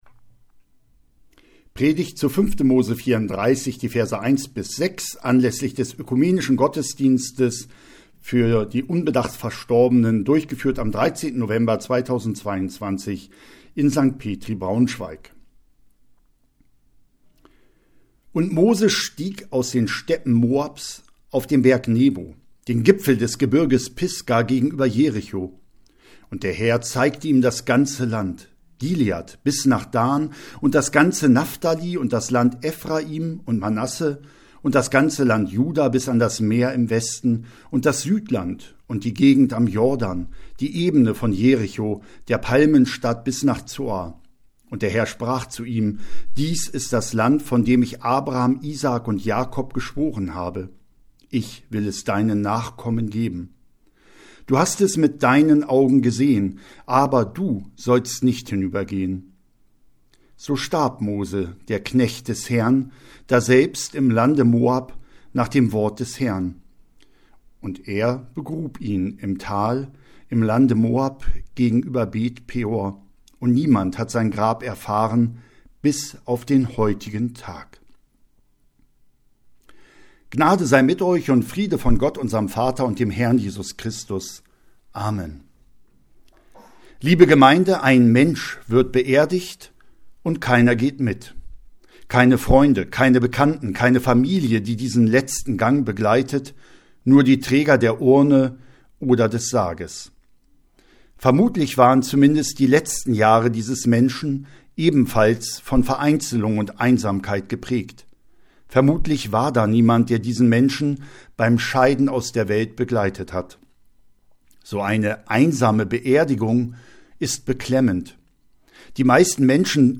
fand in diesem Jahr am Volkstrauertag, So, den 13.11.2022 statt
(Fotos des Gottesdienstes unten als PDF-Download. / Die Predigt kann unten nach-gehört werden).
14.11.2022 Kategorie: Propstei Der ökumenische Gottesdienst für Unbedachte fand in diesem Jahr am Volkstrauertag, So, den 13.11.2022 statt In der St. Petrikirche fand der diesjährige Gottesdienst der unbedacht Verstorbenen unserer Stadt statt.